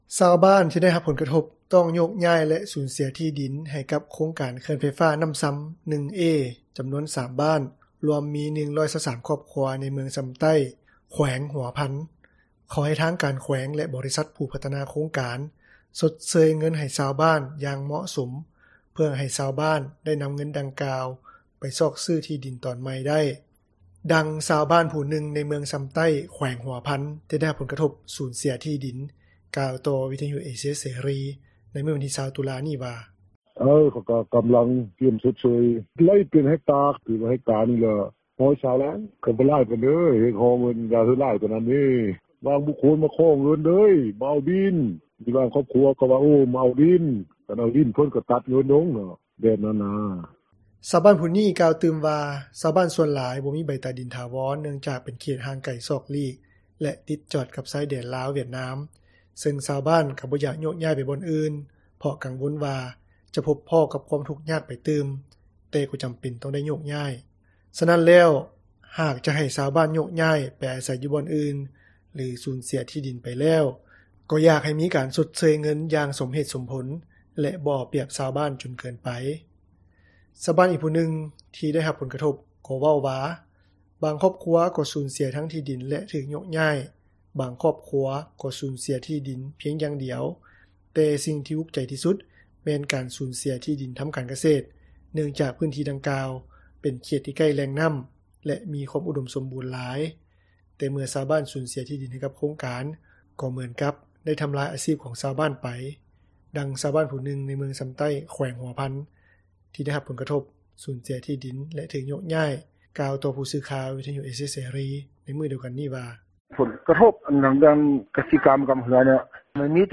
ຊາວບ້ານທີ່ໄດ້ຮັບຜົລກະທົບ ຕ້ອງໂຍກຍ້າຍ ແລະ ສູນເສັຽທີ່ດິນ ໃຫ້ກັບໂຄງການເຂື່ອນໄຟຟ້ານ້ຳຊຳ 1A ຈຳນວນ 3 ບ້ານ ລວມມີ 123 ຄອບຄົວ ໃນເມືອງຊຳໃຕ້ ແຂວງຫົວພັນ ຂໍໃຫ້ທາງການແຂວງ ແລະ ບໍຣິສັດຜູ້ພັທນາໂຄງການ ຊົດເຊີຍເງິນໃຫ້ຊາວບ້ານ ຢ່າງເໝາະສົມ ເພື່ອໃຫ້ຊາວບ້ານ ໄດ້ນຳເງິນດັ່ງກ່າວ ໄປຊອກຊື້ທີ່ດິນຕອນໃໝ່ໄດ້. ດັ່ງຊາວບ້ານຜູ້ນຶ່ງ ໃນເມືອງຊຳໃຕ້ ແຂວງຫົວພັນ ທີ່ໄດ້ຮັບຜົລກະທົບ ສູນເສັຽທີ່ດິນ ກ່າວຕໍ່ຜູ້ສື່ຂ່າວ ວິທຍຸເອເຊັຽເສຣີ ໃນມື້ວັນທີ 20 ຕຸລາ ນີ້ວ່າ:
ດັ່ງເຈົ້າໜ້າທີ່ ເມືອງຊຳໃຕ້ ແຂວງຫົວພັນ ທ່ານນຶ່ງ ກ່າວຕໍ່ວິທຍຸເອເຊັຽເສຣີ ໃນມື້ດຽວກັນນີ້ວ່າ: